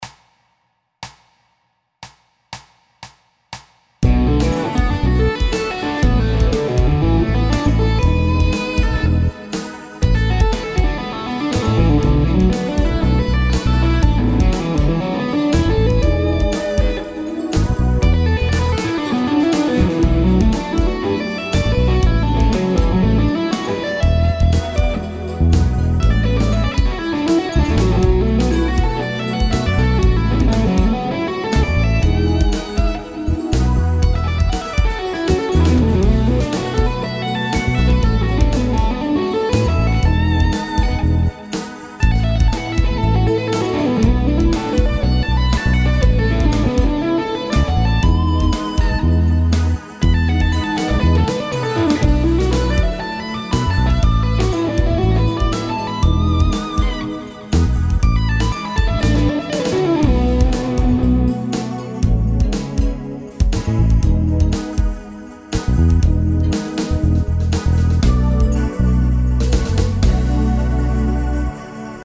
The concept here is to play pentatonics using String skipping and tapping for interesting and smooth sound.
All licks are played using 8th triplets and 16th notes plus 3 note-per-string concept.
The idea is that the 3rd note of each string is tapped, so you can reach in the same time a fluid and clear sound of your licks.
I have played all exercises in a tune and the backing track is also available.